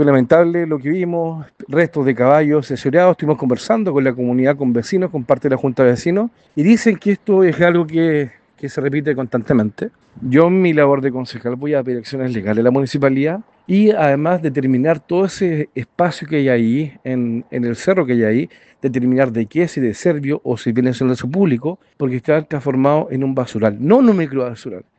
Por lo mismo, el concejal de Concepción, Miguel Ángel Berrios, se dirigió al lugar y señaló que frente a lo sucedido, oficiará a la municipalidad para que se tomen acciones legales por insalubridad.